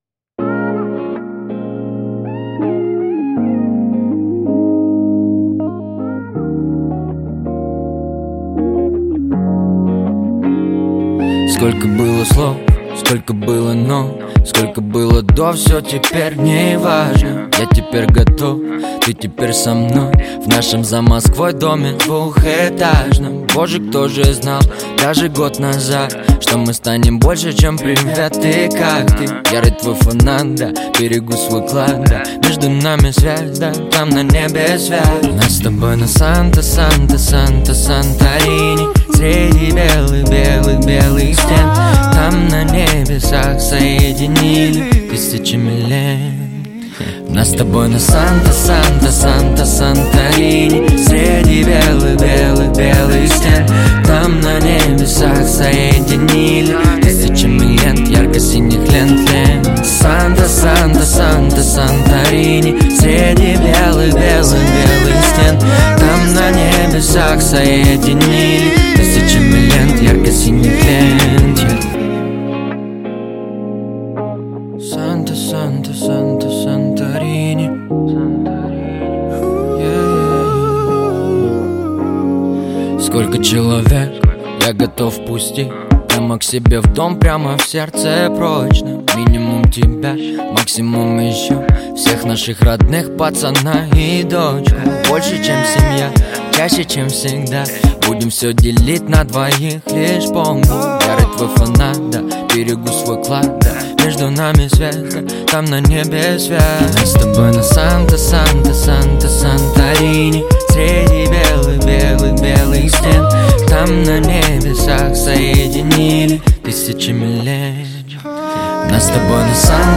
Жанр: Русский рэп / Хип-хоп